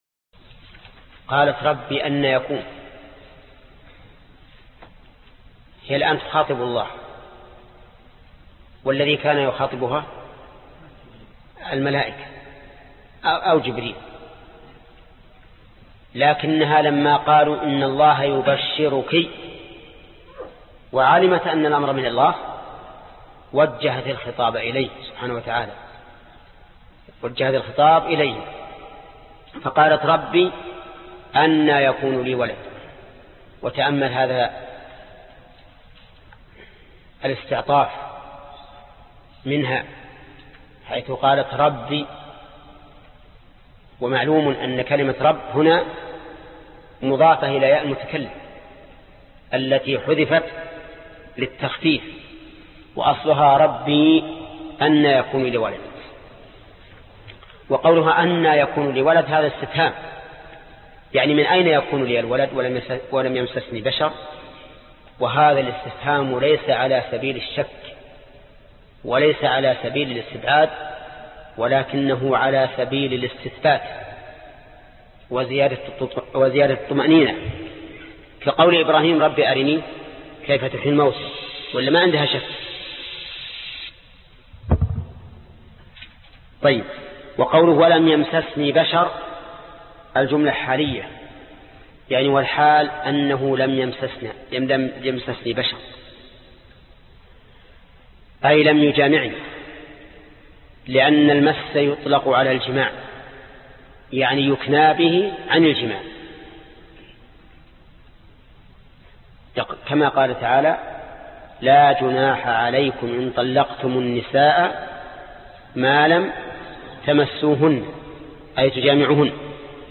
الدرس 85 الأية 47 ( تفسير سورة آل عمران ) - فضيلة الشيخ محمد بن صالح العثيمين رحمه الله